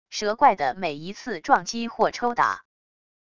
蛇怪的每一次撞击或抽打wav音频